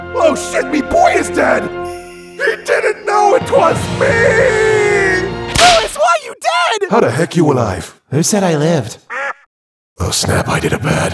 Voice over
Music Furueru Kokoro – Asura’s Wrath